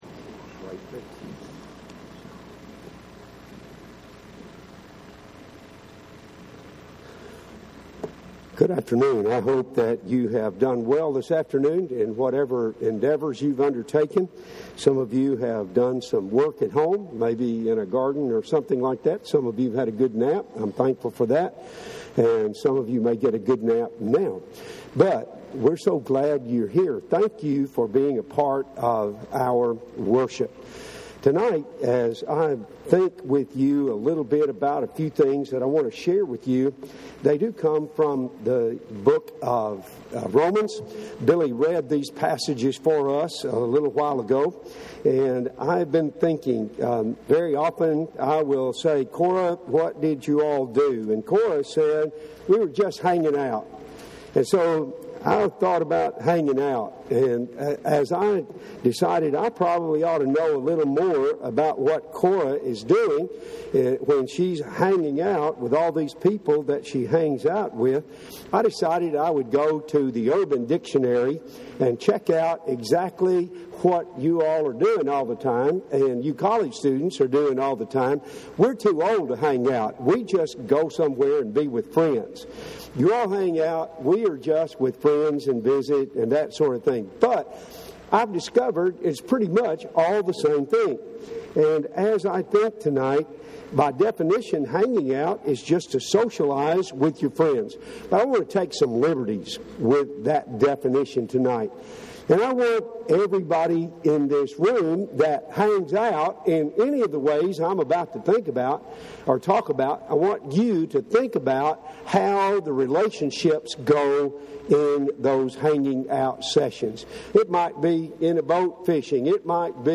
– Henderson, TN Church of Christ